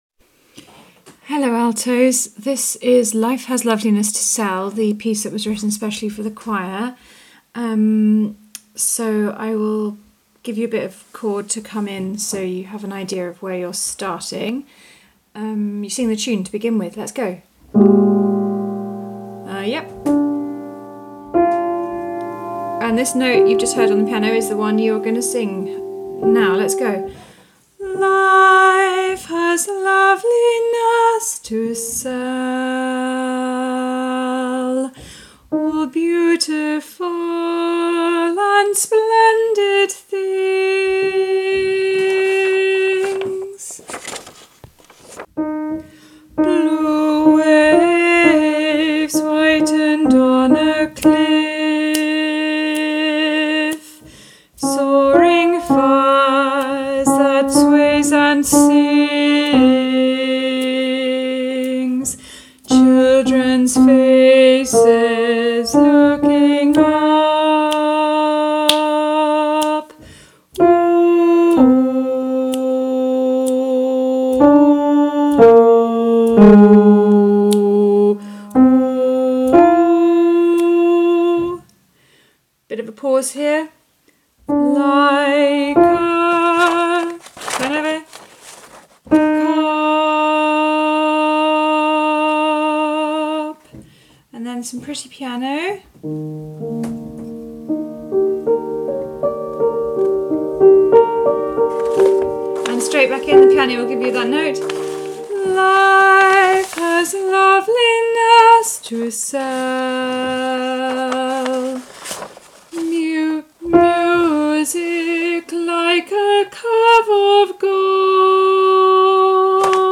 The composer has sent us this computer generated score to listen to
Altos